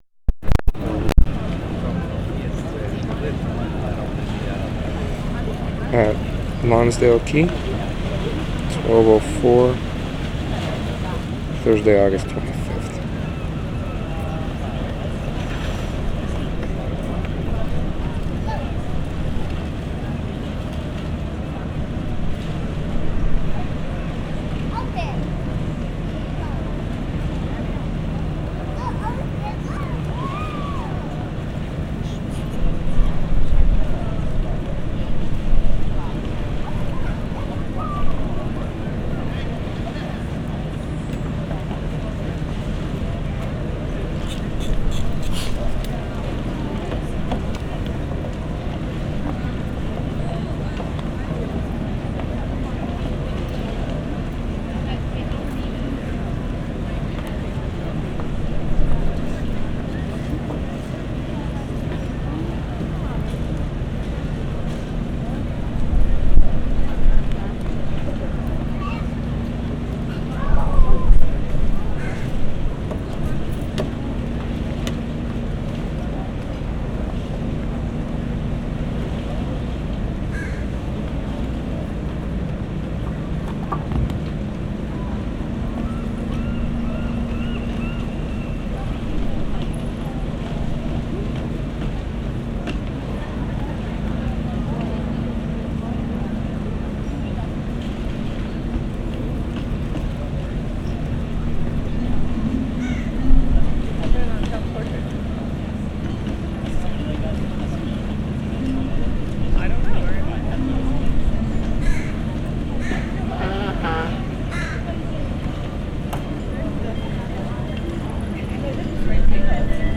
LONSDALE QUAY MARKET, AUGUST 25/2011
Park Bench Ambience I, 2:02
1. Sitting on a park bench in the market area, outdoors, many people in the area - tourists, etc, voices, footsteps, boat engine - ambience, seagull at 1:25, music played by busker.